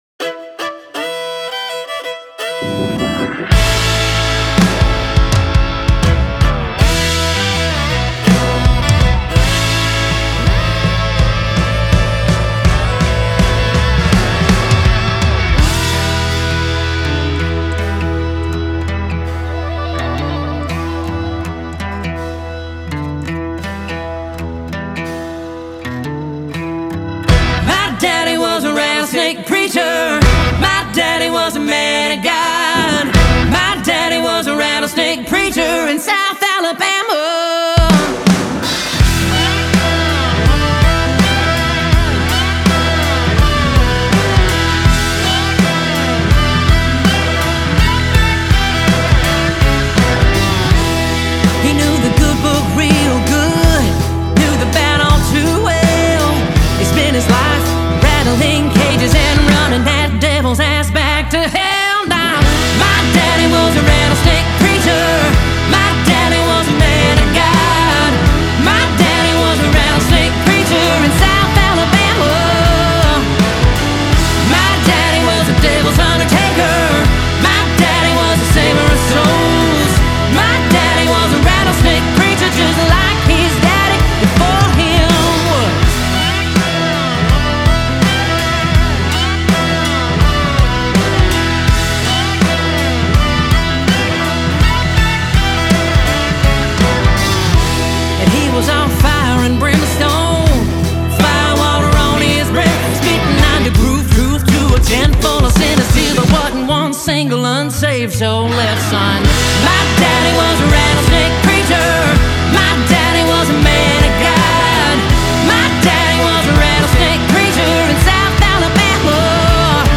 Трек размещён в разделе Зарубежная музыка / Кантри.